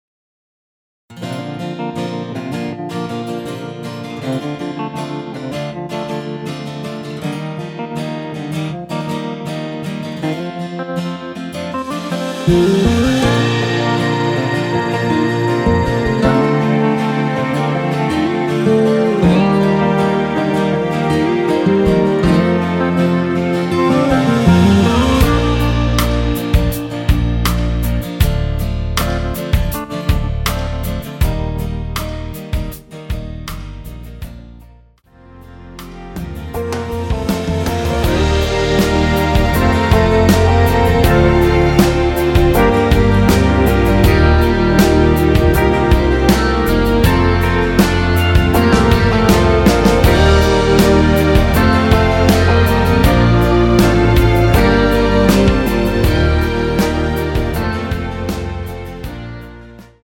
Bb
앞부분30초, 뒷부분30초씩 편집해서 올려 드리고 있습니다.
중간에 음이 끈어지고 다시 나오는 이유는